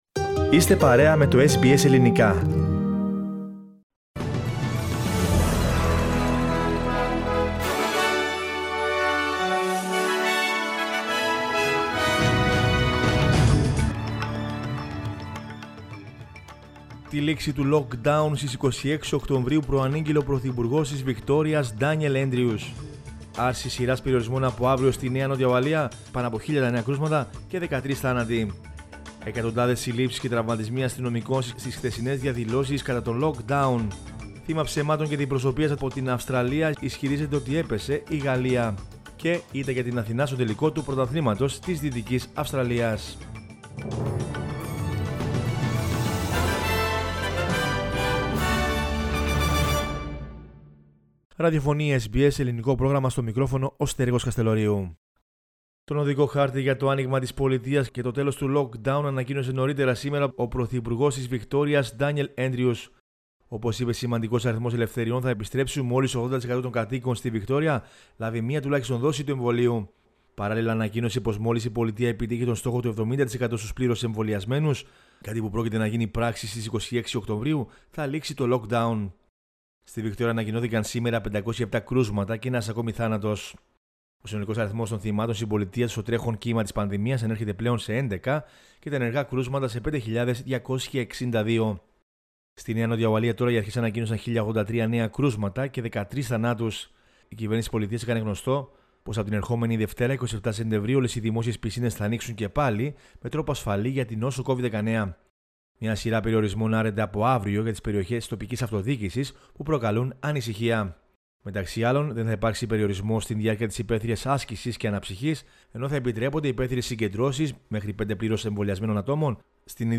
Δελτίο Ειδήσεων 19.09.21